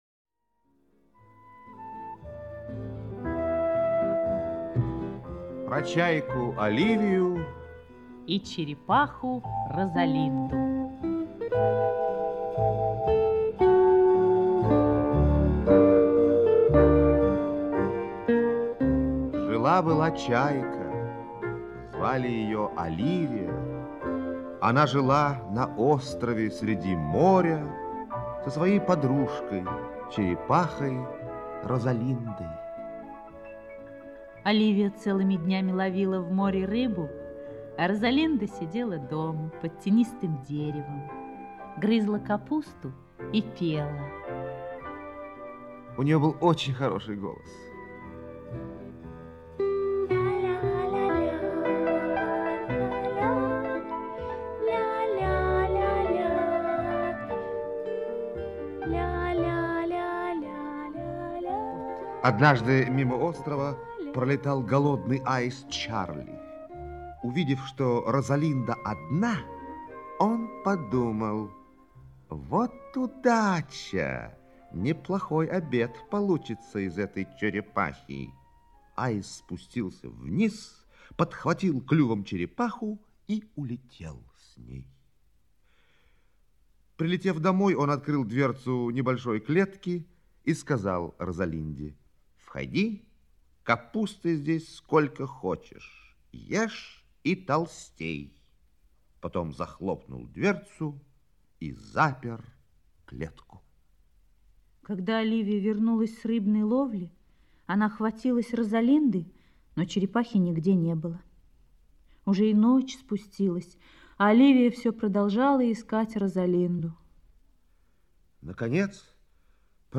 Аудиосказка «Про чайку Оливию и черепаху Розалинду»